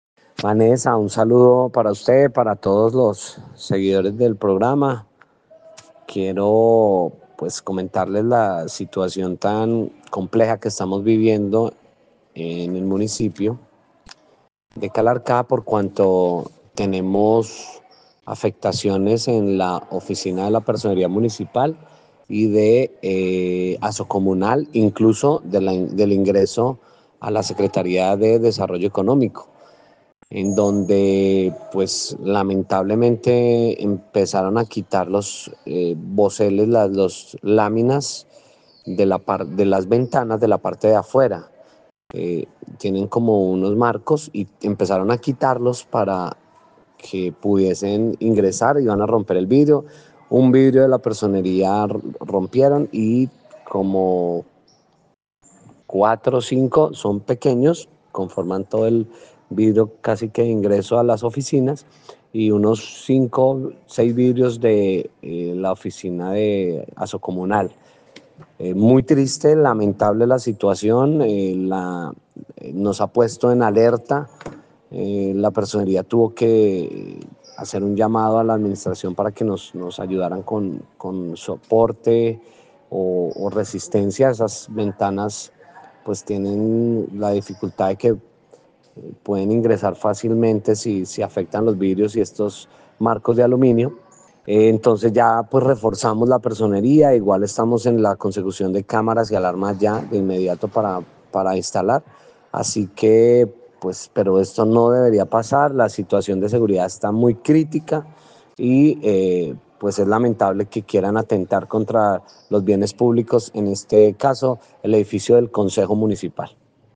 Personero de Calarcá